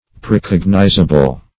Precognizable \Pre*cog"ni*za*ble\, a.